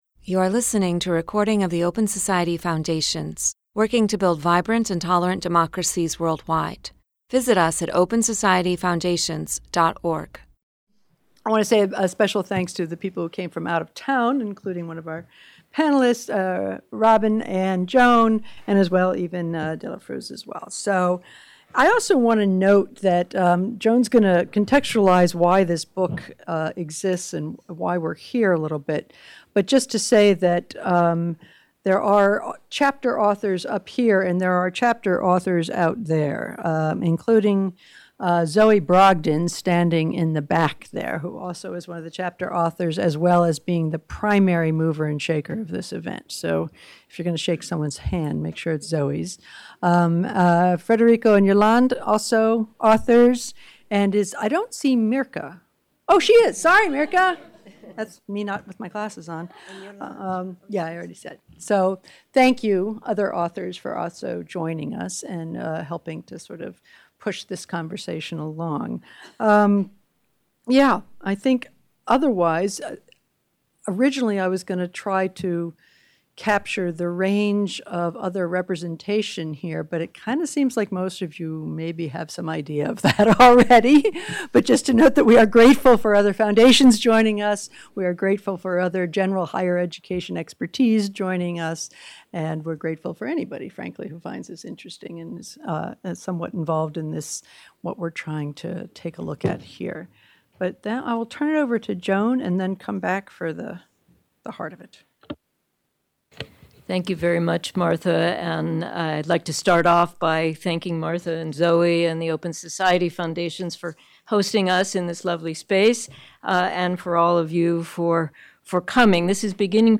Book-Launch—International-Scholarships-in-Higher-Education_-Pathways-for-Social-Change.mp3